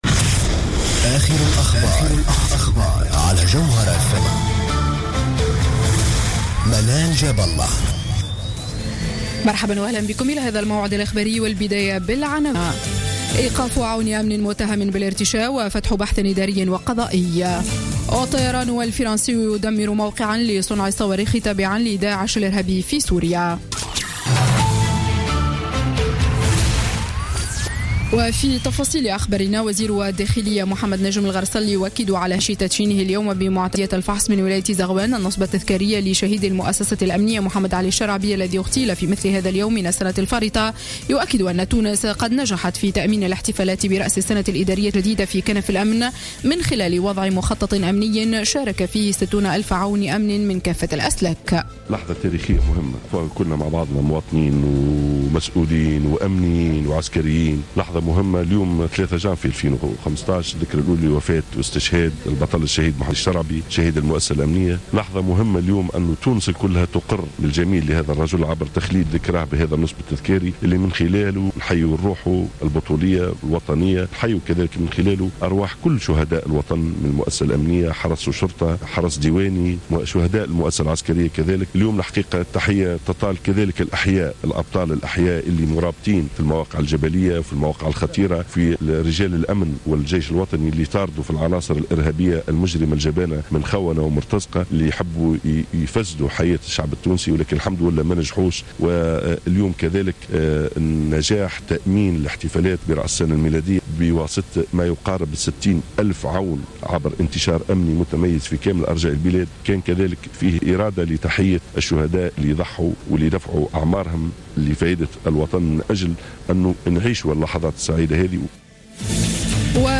Journal Info 19h00 du dimanche 03 janvier 2015